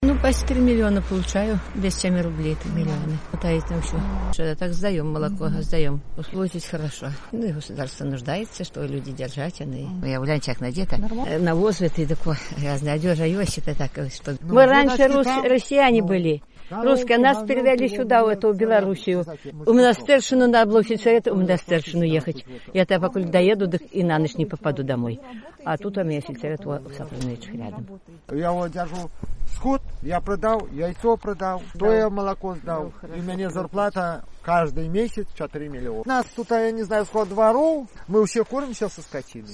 Гаворка жыхароў вёскі Каськова, што на беларускім баку мяжы, мала чым адрозьніваецца ад гаворкі суседзяў з расейскага боку.
У Каськове тое самае яканьне, дзеканьне ды [ў] нескладовае. Слоўны запас, аднак, дужа папоўнены расейшчынай.